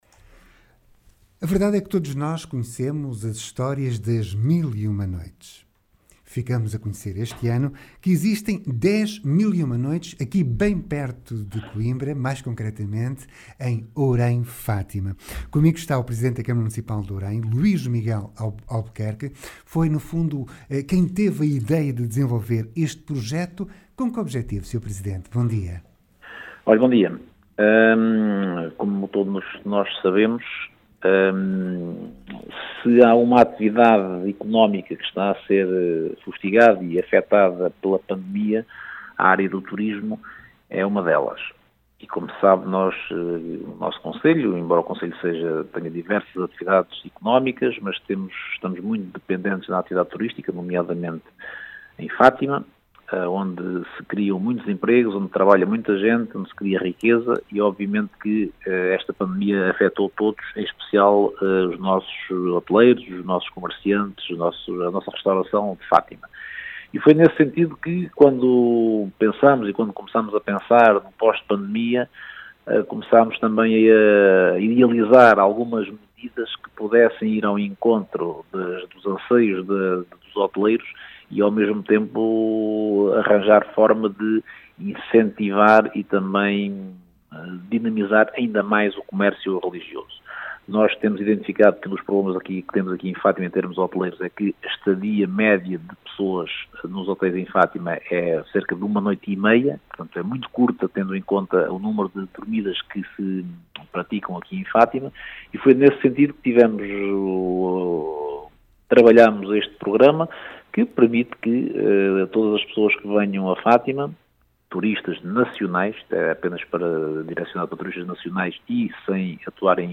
A Rádio Regional do Centro conversou com Luís Miguel Albuquerque, presidente da Câmara Municipal de Ourém, sobre a “Campanha 10 001 Noites”.